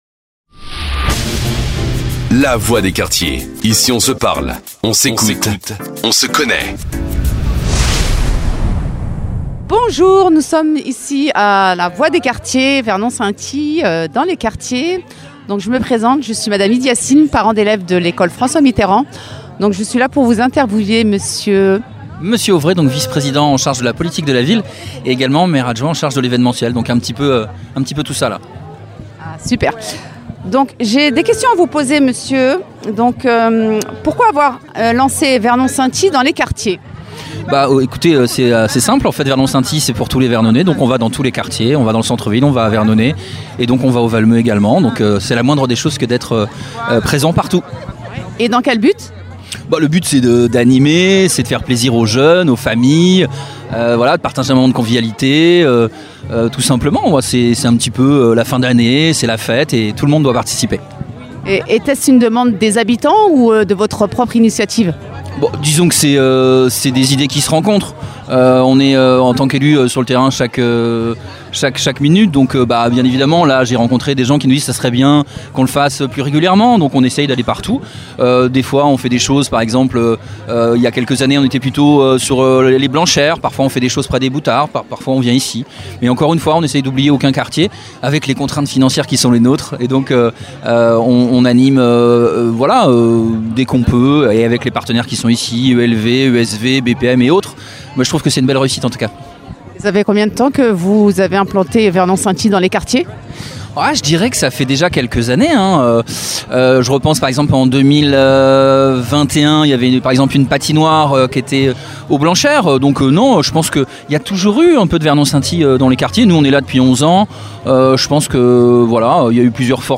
VERNON SCINTILLE DANS LES QUARTIERS INTERVIEW JOHAN AUVRAY VICE PRESIDENT SNA EN CHARGE DE LA POLITIQUE DE LA VILLE